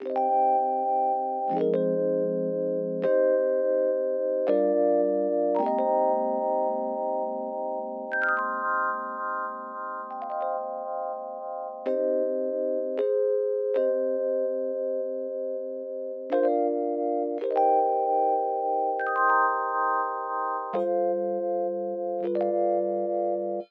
11 rhodes B.wav